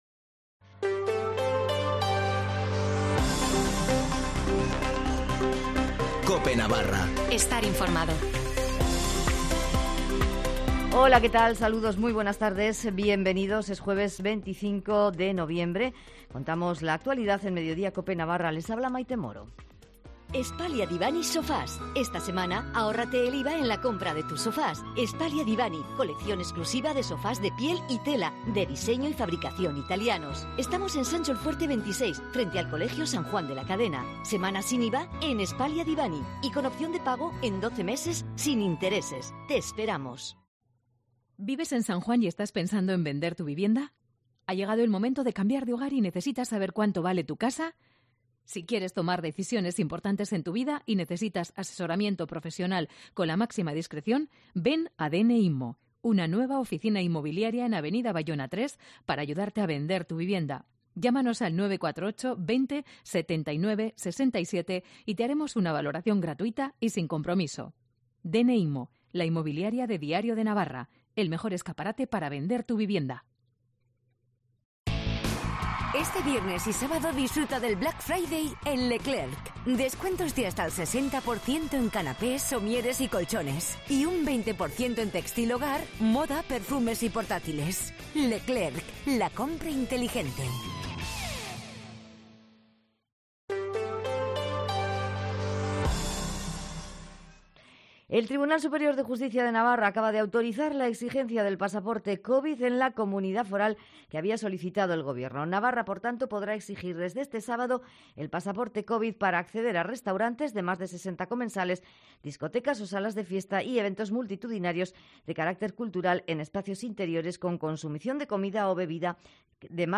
Informativo de las 14:20 en Cope Navarra (25/11/2021)